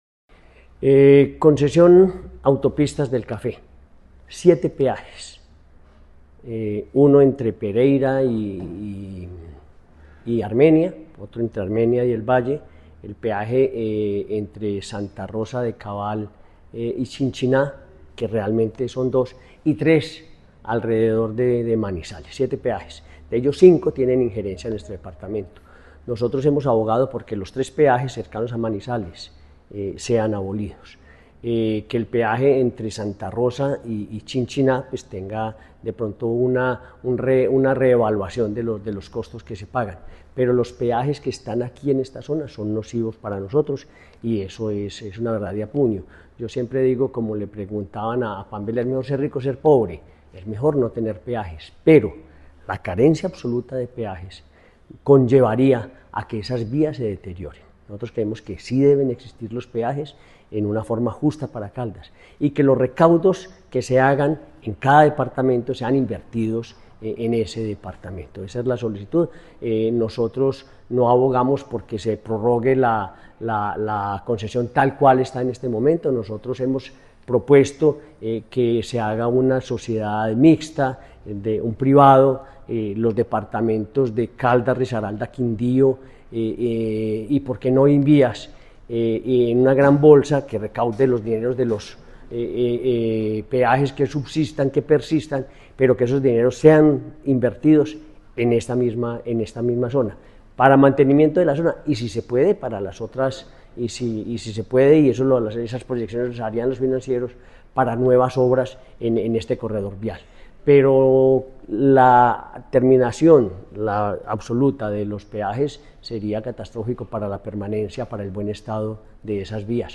Gobernador de Caldas, Henry Gutiérrez Ángel.